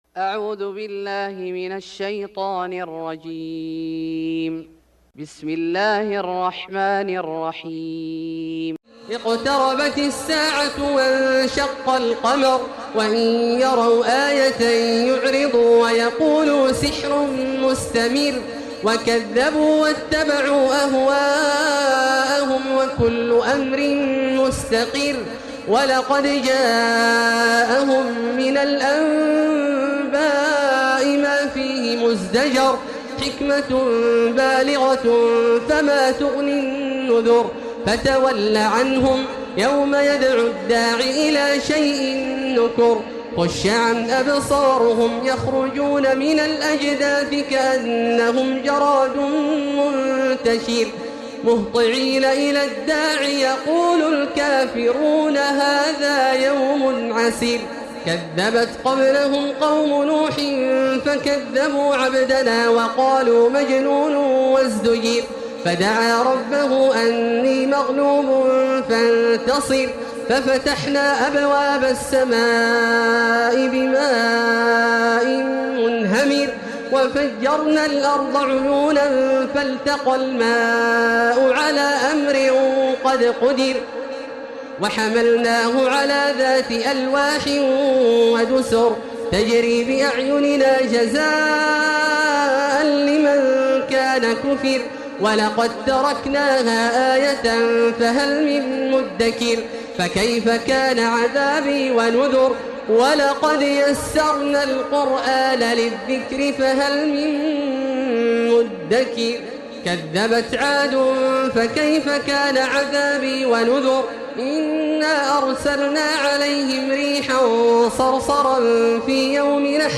سورة القمر Surat Al-Qamar > مصحف الشيخ عبدالله الجهني من الحرم المكي > المصحف - تلاوات الحرمين